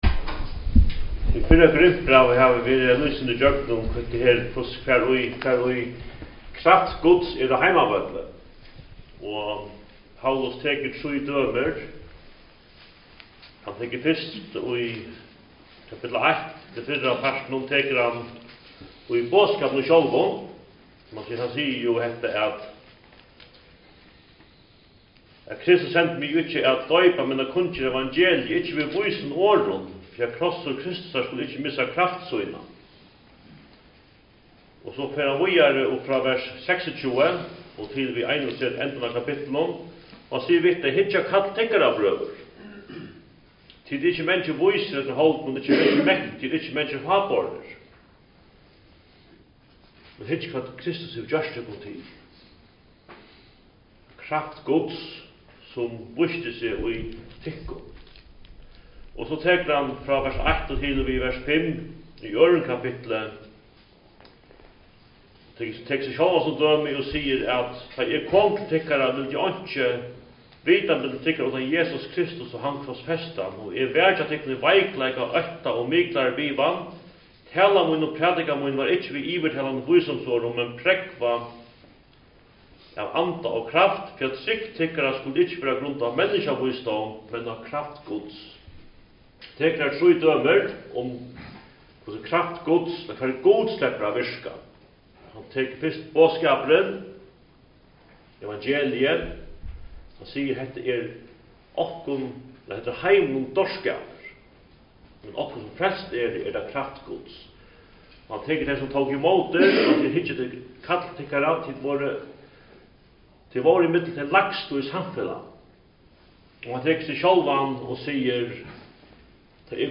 Talur (Fø)